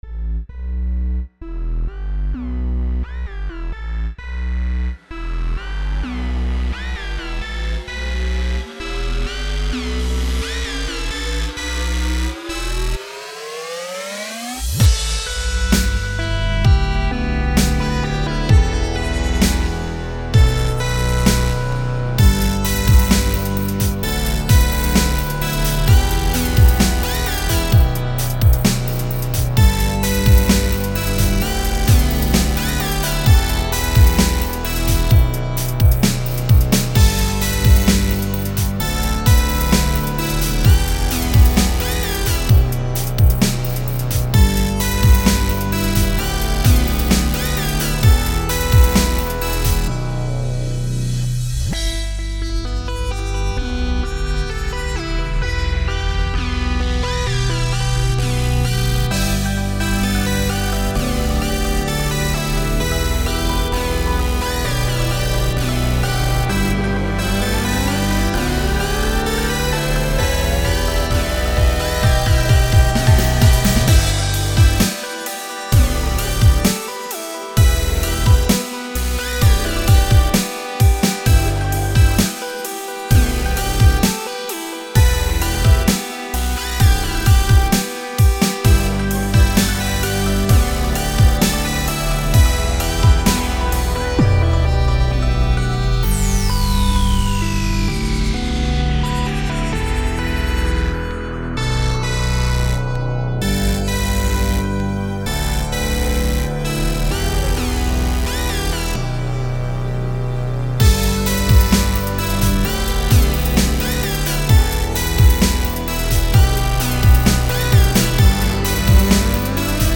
what came of it was this funky sounding track.. But what I was interested in was the lead. it was grungy and it was down right sick..